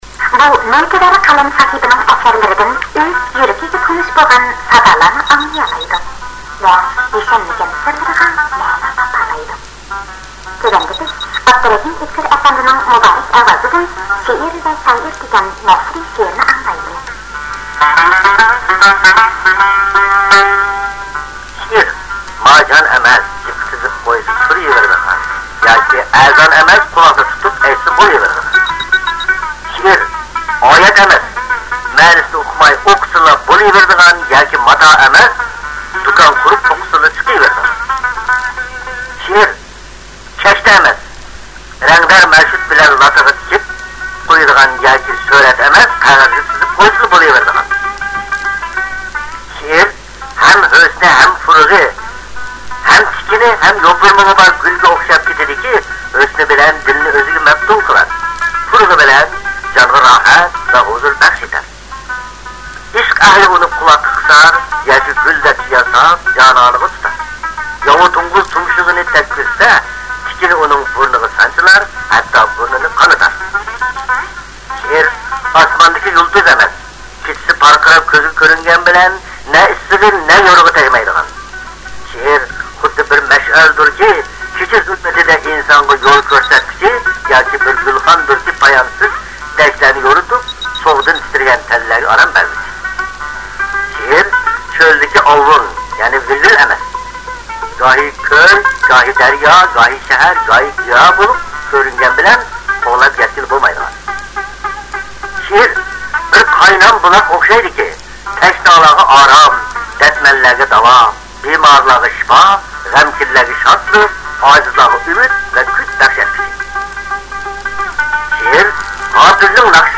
شائىر ئۆلمەيدۇ (ئا. ئۆتكۈر قاتارلىقلار دېكلىماتسىيىسى)
بۇ شېئىرلارنىڭ ئاپتۇرى : ئابدۇرېھىم ئۆتكۈر، ئۆزى ئوقۇغان. چاپقاق مۈشۈك بىلەن چاشقان (شېئىرىي مەسەل) _ ئاپتۇرى: زۇنۇن قادىرى، ئۆزى ئوقۇغان. ئۇچتۇرپان ئالتۇن ئۈزۈك (چاتما شېئىر)_ئابدۇكېرىم خۇجا، ئۆزى ئوقۇغان. ①شېئىر توغرىسىدا ②ئانا تىلىم ③مىللەت ھەققىدە ④بىزار بولدۇم بۇ شېئىرلارنىڭ ئاپتۇرى: تىيىپچان ئىليۇپ، ئۆزى ئوقۇغان.